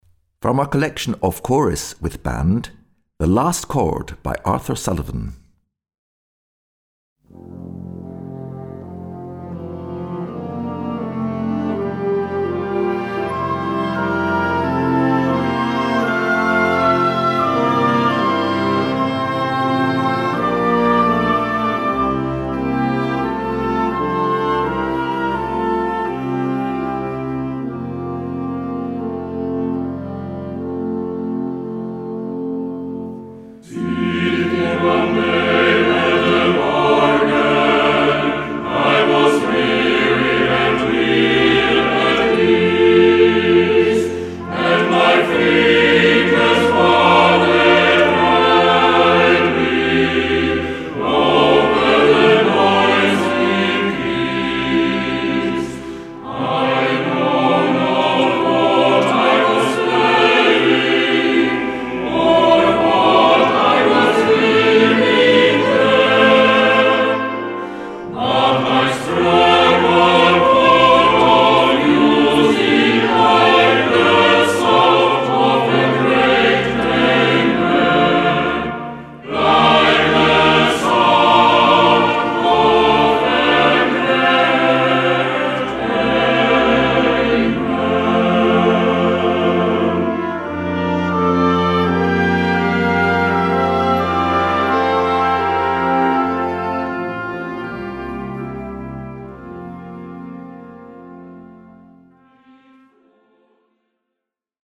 Gattung: SATB
Besetzung: Blasorchester
Organ optional.